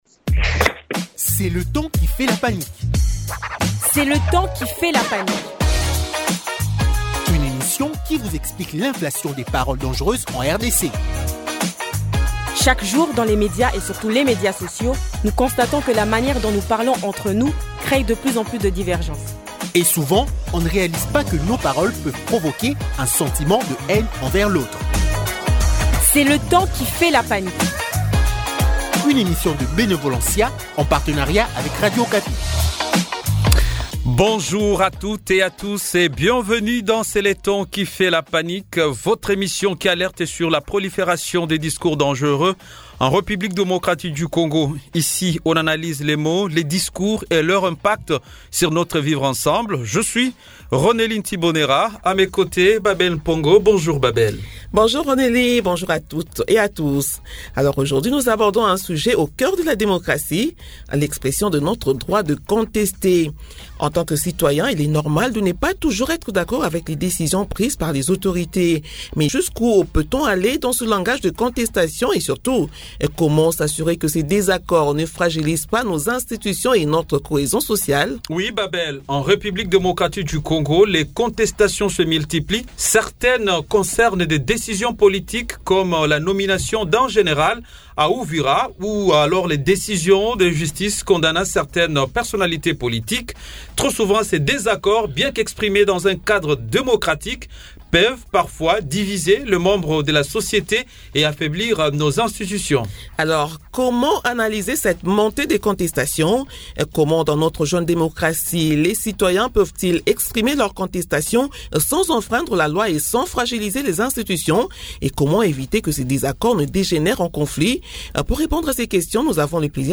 Pour en parler, deux invités :